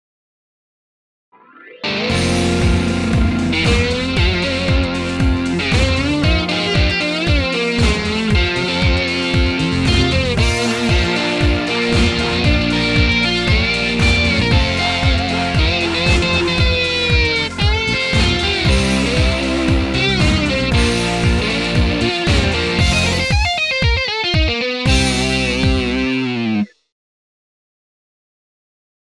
I’ve created for you some examples of the described sliding imitation technique, combined with
some other tricks – like string bending imitation.